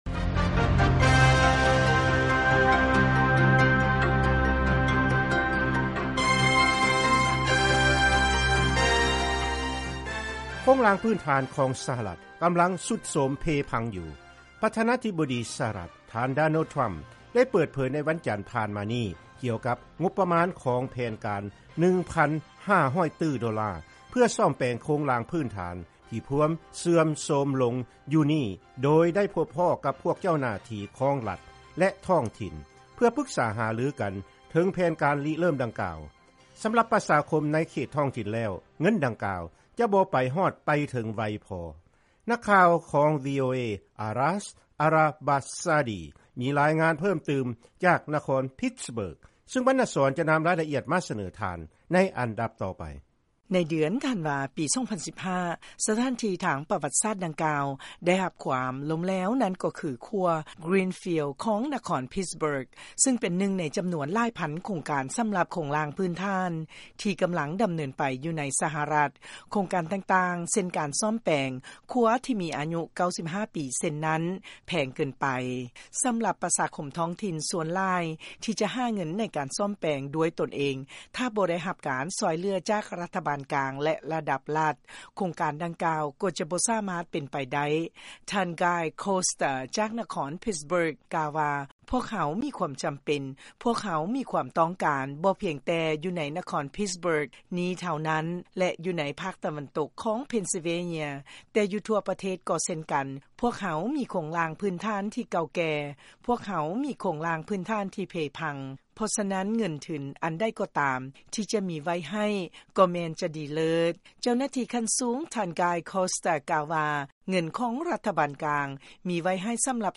ລາຍງານກ່ຽວກັບງົບປະມານໃນການບູລະນະໂຄງລ່າງພື້ນຖານຂອງສະຫະລັດ